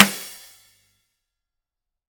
drum-hitnormal.ogg